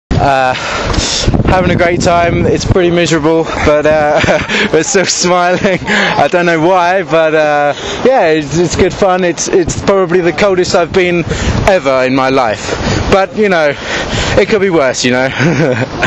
More interviews… – Formula Windsurfing